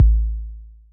Blow_Kick.wav